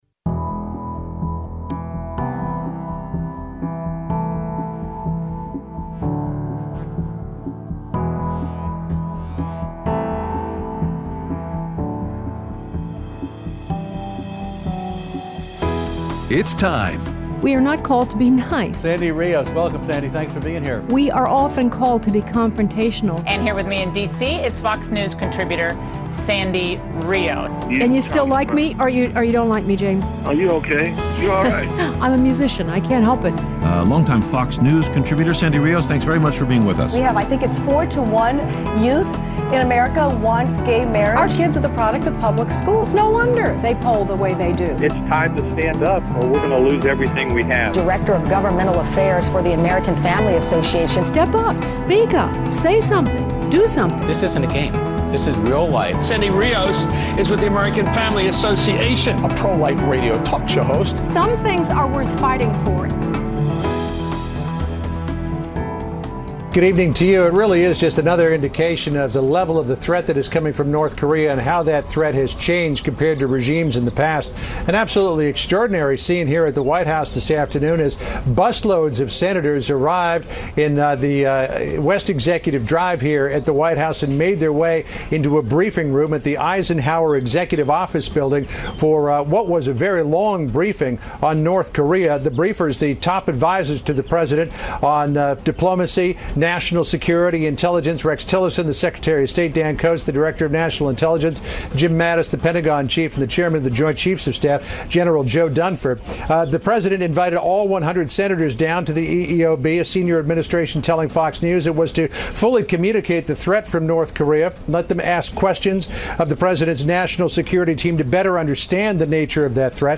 Aired Thursday 4/27/17 on AFR 7:05AM - 8:00AM CST